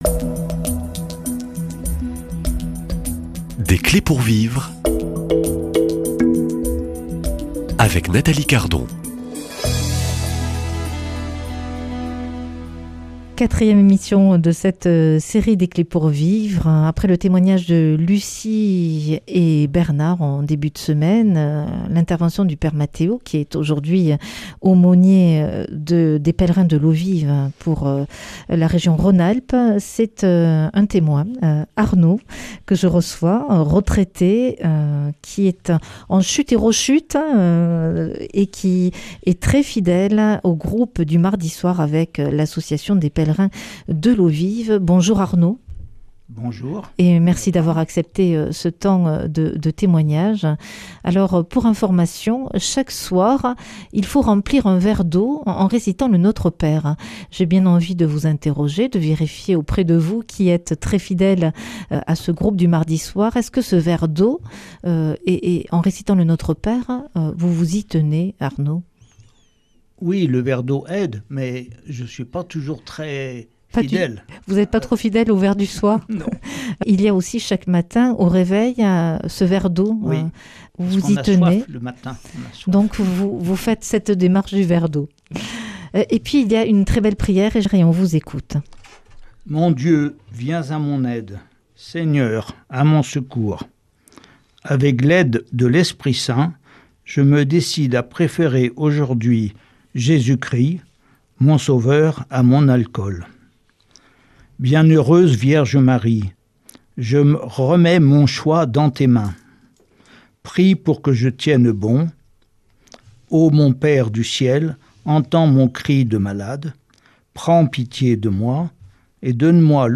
Témoignages